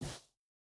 Shoe Step Snow Medium E.wav